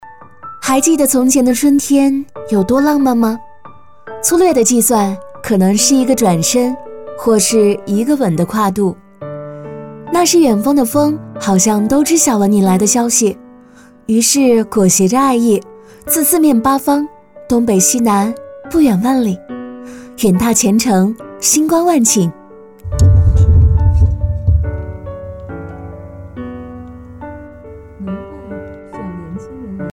旁白-女10-还记得春天有多浪漫.mp3